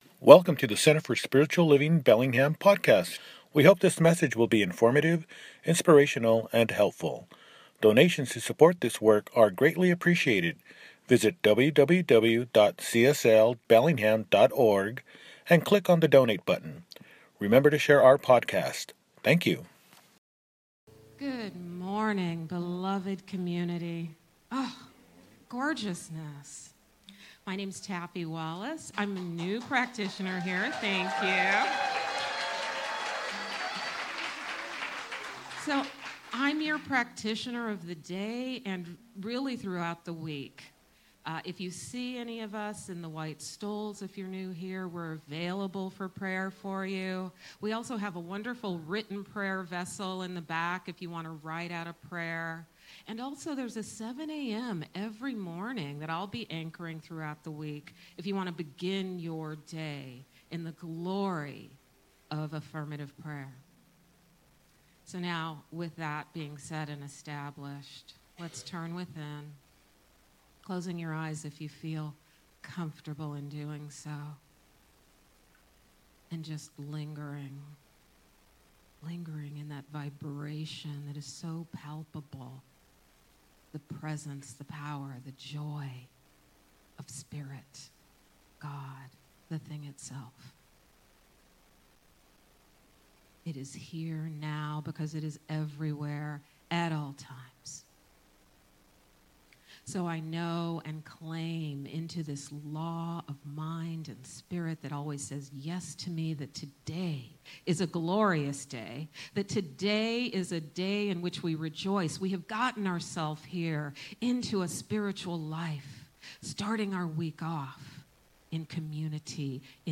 Travelling in a World of My Creation – Celebration Service
Mar 1, 2026 | Podcasts, Services